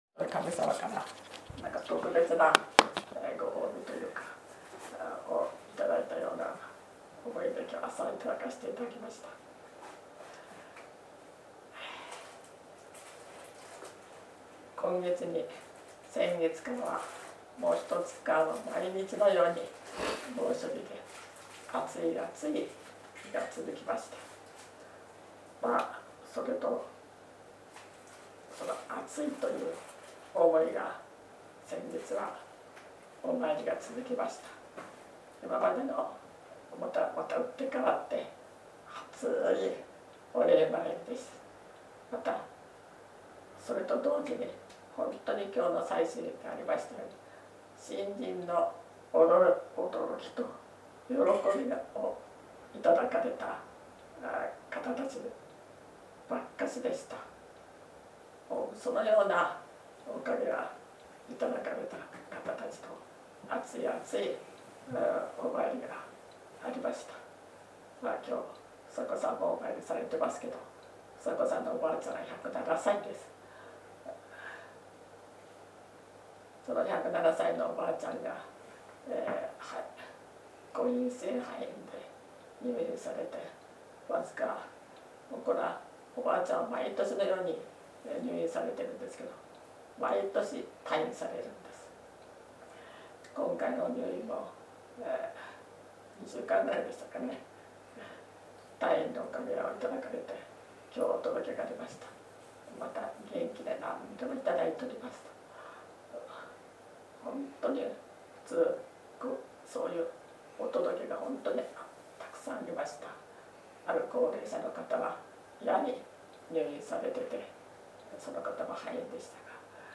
月例祭教話